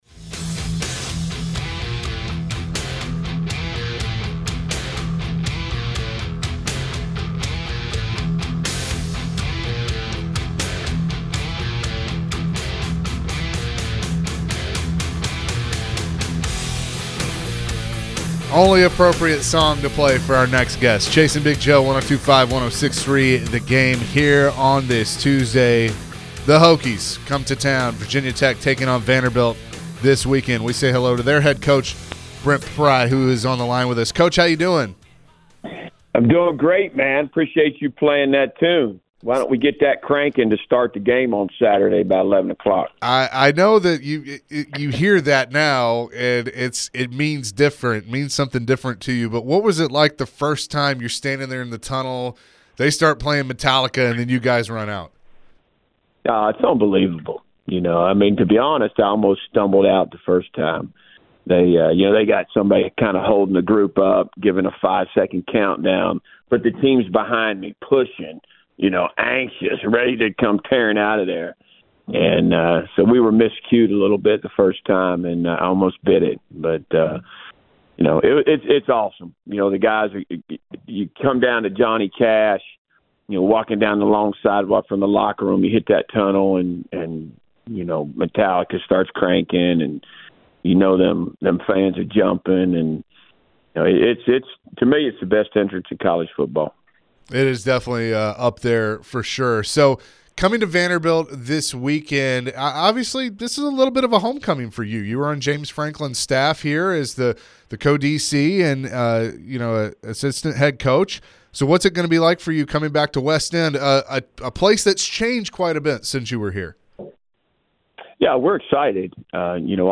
Virginia Tech head football coach Brent Pry joined the Chase & Big Joe Show to preview their upcoming matchup with Vanderbilt.